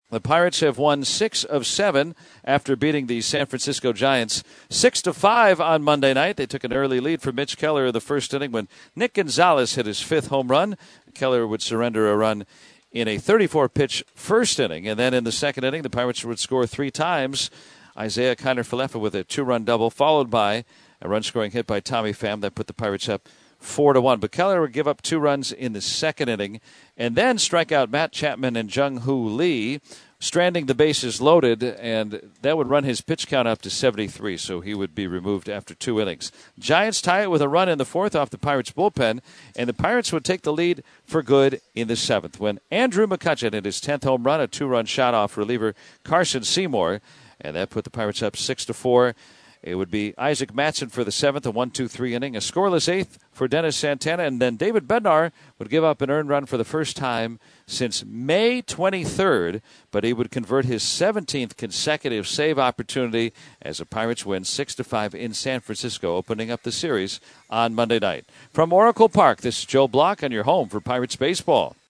recaps a big night on the bayfront in San Francisco